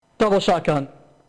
double shotgun